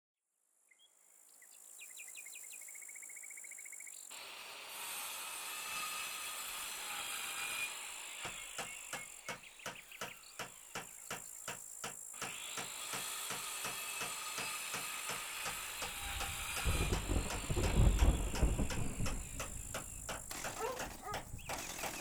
Здесь вы найдете шумы инструментов, техники и обстановки строительной площадки.
Звуки строительных работ в деревне или загородном доме атмосферно